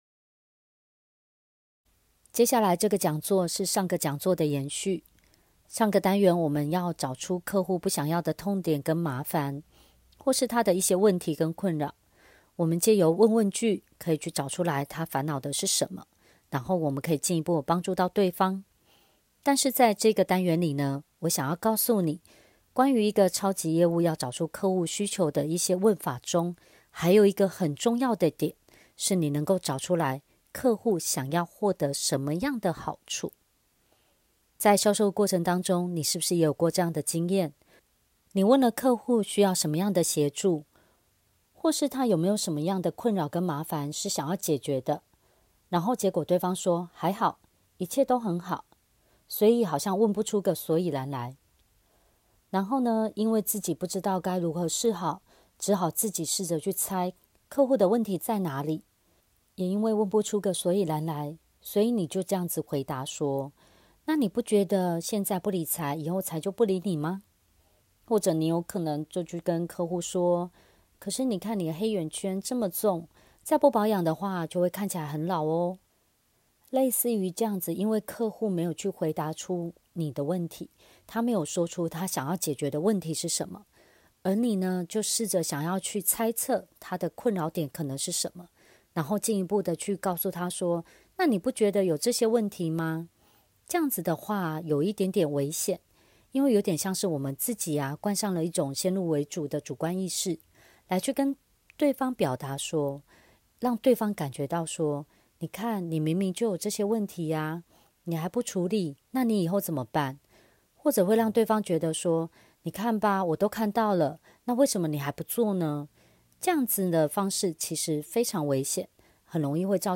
講座10：超級業務找出客戶需求的超強問法：-想獲得什麼好處？.mp3